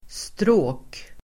Ladda ner uttalet
Uttal: [strå:k]